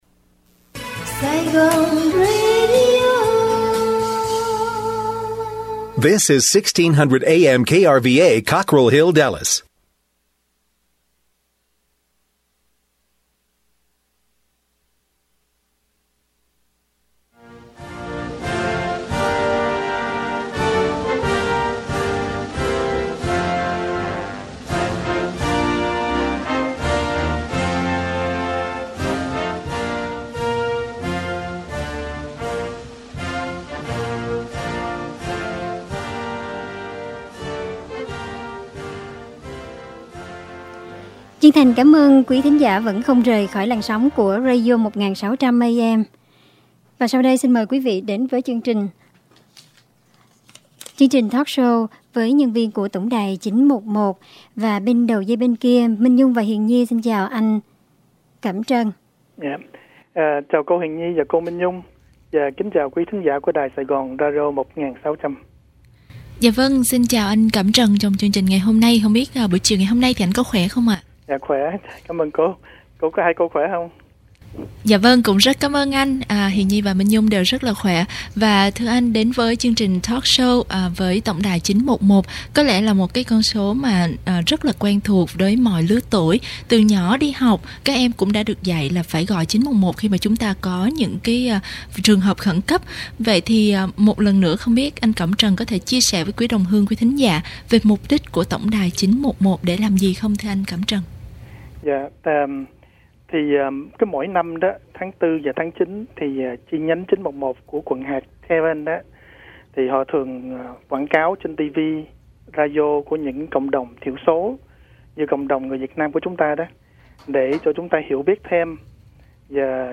Phóng Sự: Lễ Tưởng Niệm 911 tại Groud Zero, Nữu Ước - Sept 11, 2010